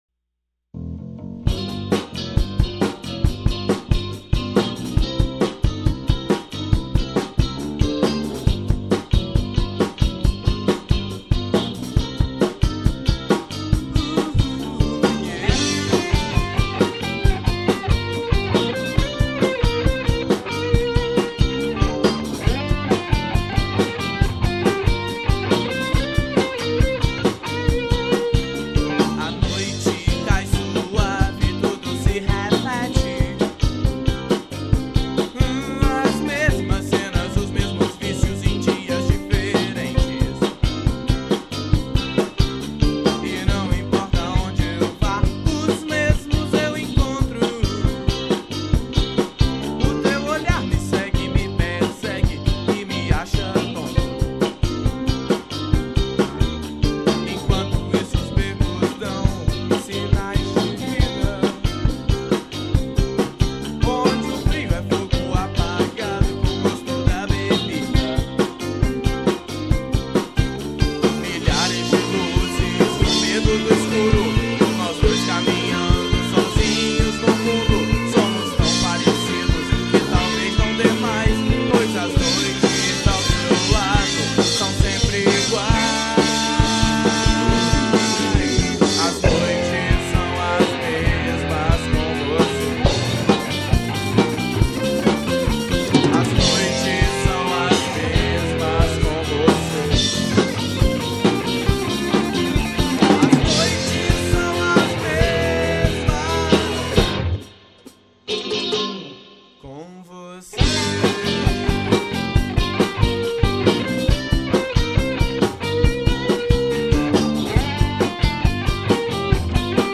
Tocávamos rock nacional dos anos 80 e 90
A qualidade da gravação é ruim, mas a música é joia!